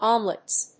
発音記号・読み方
/ˈɑmlʌts(米国英語), ˈɑ:mlʌts(英国英語)/